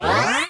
SE_KILLER_POWERUP2.wav